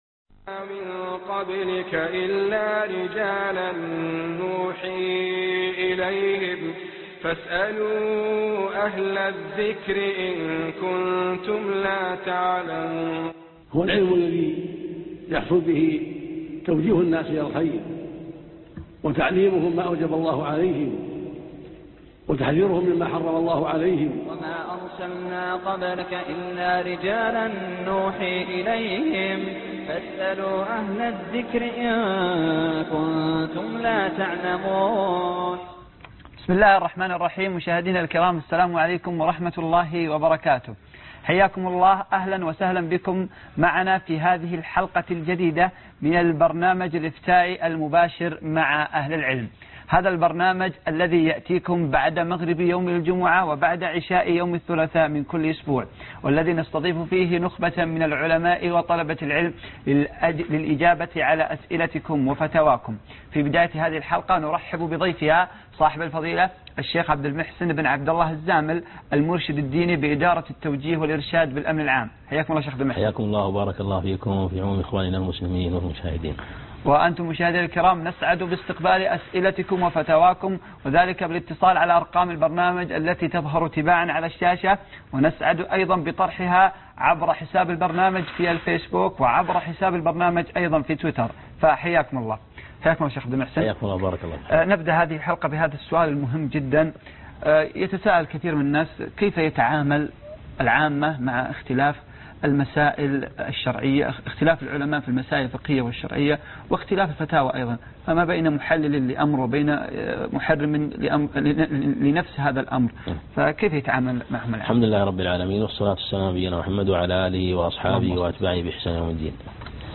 الدرس 4 كيف يتعامل العامة مع إختلاف الأوامر الشرعية - مع أهل العلم